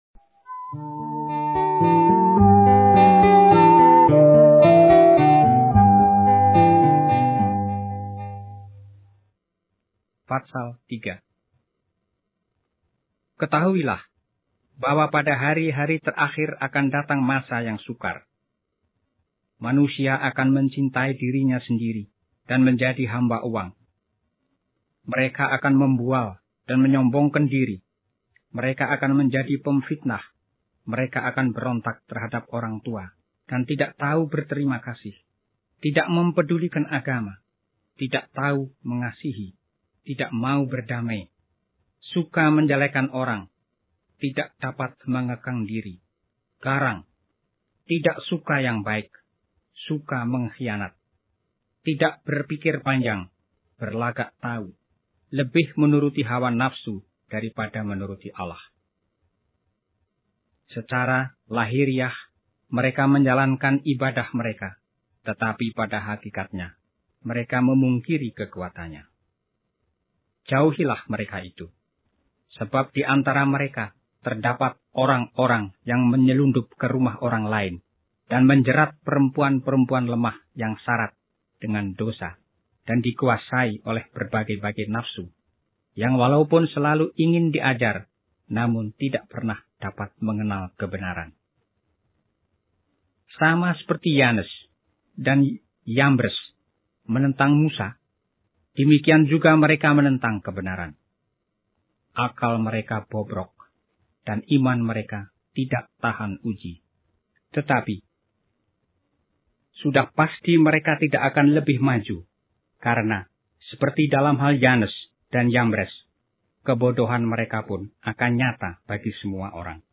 Alkitab AUDIO -- 2 Timotius 03